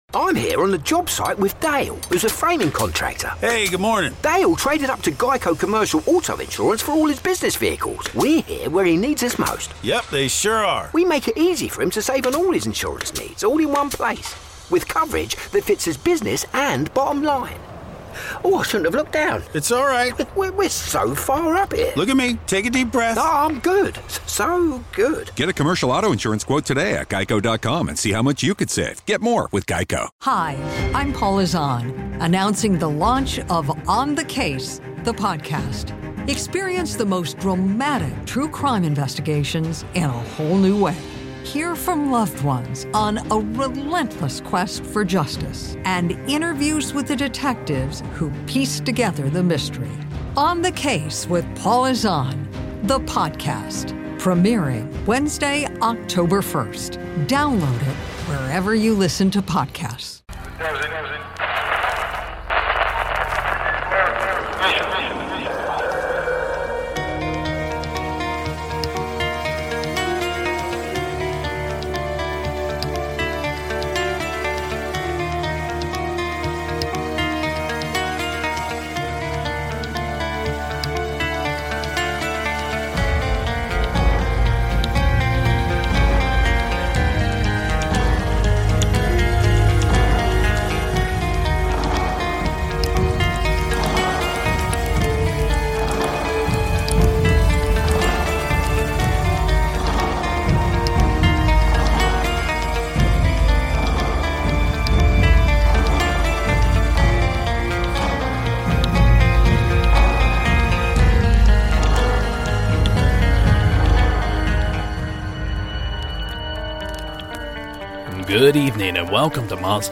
Witnesses of bigfoot, sasquatch, UFOs, aliens, ghosts and an array of other creatures from the paranormal and cryptozoology realm detail their encounters.